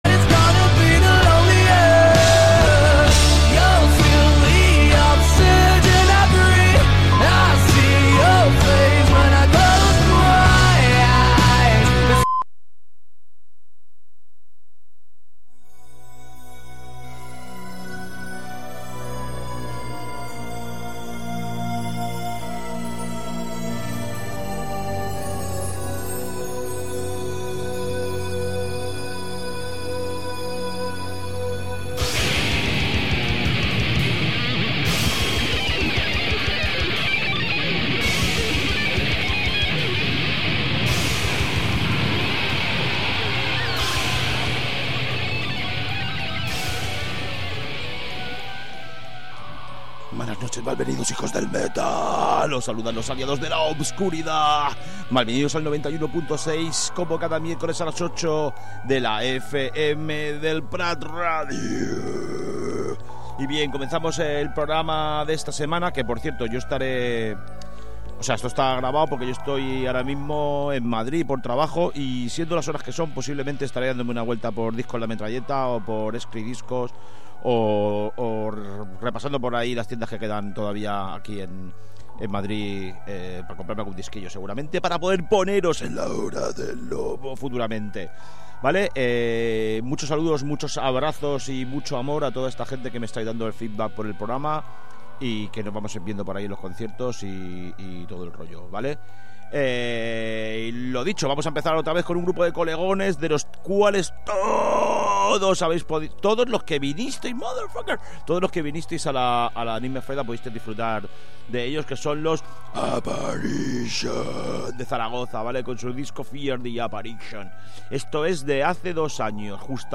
A 'La hora del lobo', ens arrossegarem per tots els subgèneres del metal, submergint-nos especialment en les variants més fosques i extremes.